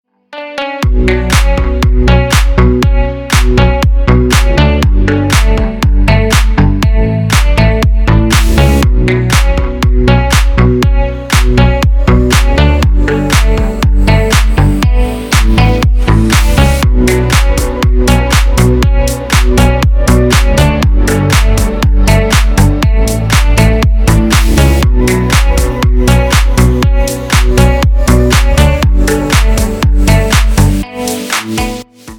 • Качество: 320, Stereo
громкие
Electronic
EDM
без слов
Стиль: deep house